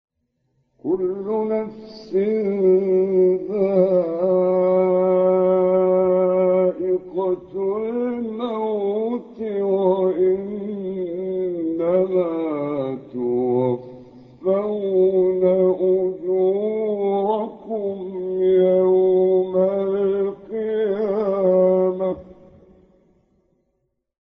12 فراز در مقام «بیات حسینی» از شیخ محمد عمران
گروه فعالیت‌های قرآنی: فرازهایی از مقام بیات حسینی با صوت محمد عمران ارائه می‌شود.
به گزارش خبرگزاری بین المللی قرآن (ایکنا)، دوازده فراز در مقام بیات حسینی از محمد عمران، قاری برجسته مصری در کانال تلگرامی این قاری مصری منتشر شده است که در زیر ارائه می‌شود.